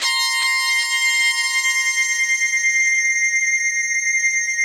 45 SYNTH 4-L.wav